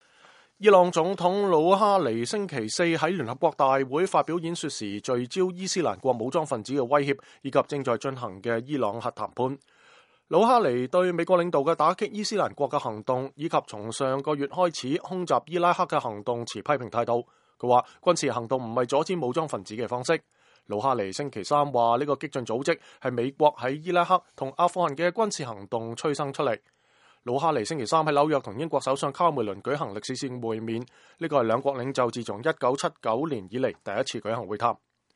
伊朗總統魯哈尼星期四在聯合國大會發表演說，聚焦伊斯蘭國武裝份子的威脅以及正在進行的伊朗核談判。魯哈尼對美國領導的打擊伊斯蘭國的行動以及從上個月開始空襲伊拉克的行動持批評態度，他說軍事行動不是阻止武裝份子的方式。